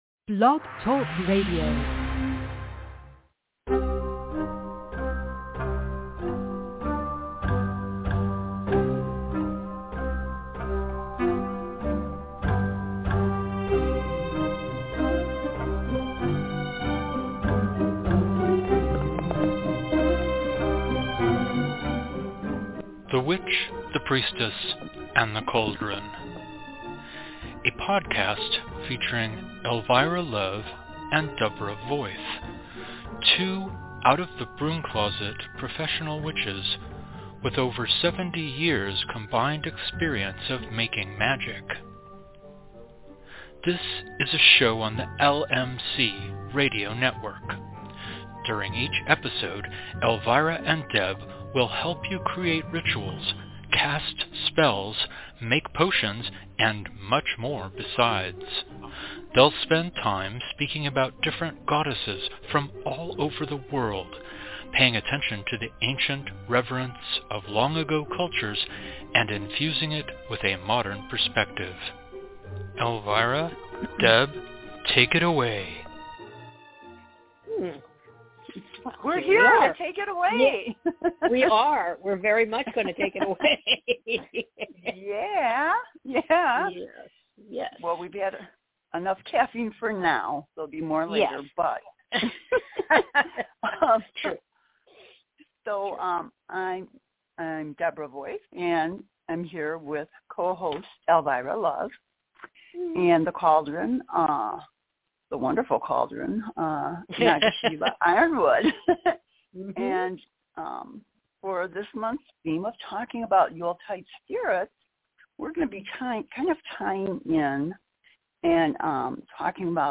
A new episode of The Witch, The Priestess, and The Cauldron air LIVE every Thursday at 4pm PT / 7pm ET!